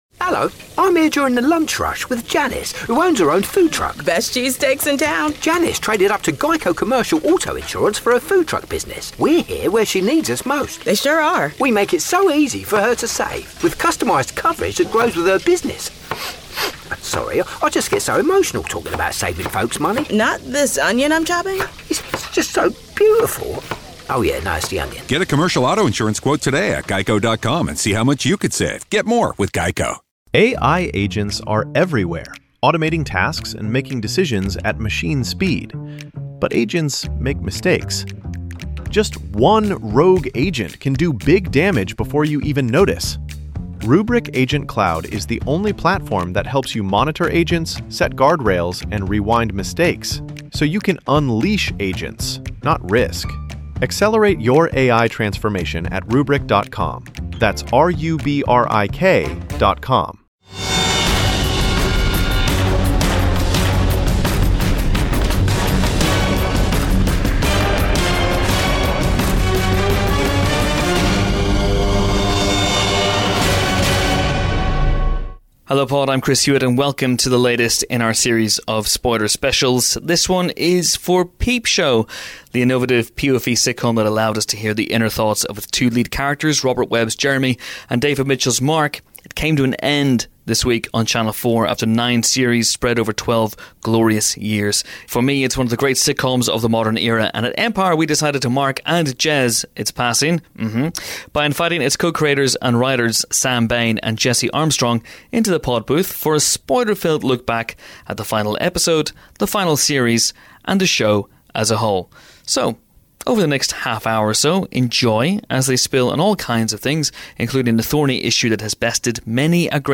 To mark this week's Peep Show finale, we invited Sam Bain and Jesse Armstrong, the show's co-creators and co-writers, to the pod booth, for a Spoiler Special.